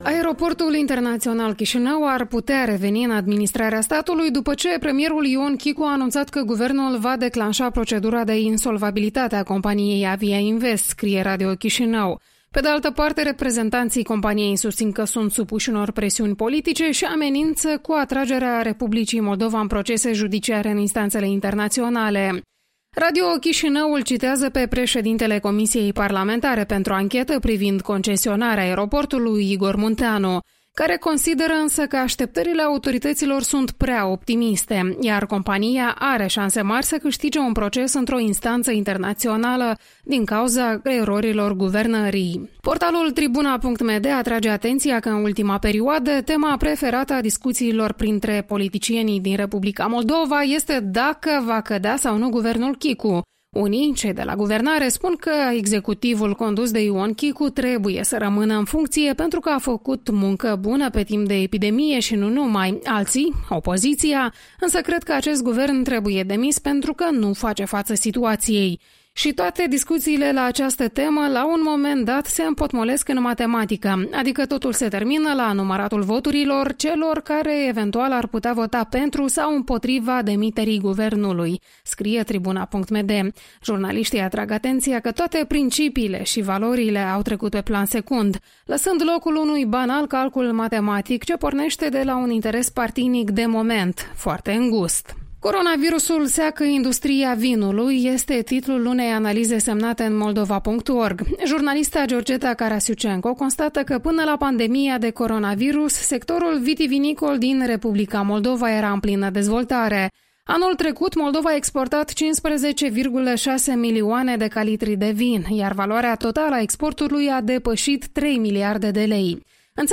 Revista presei matinale la Radio Europa Liberă.